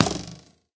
bowhit2.ogg